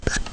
1 channel
chalk.mp3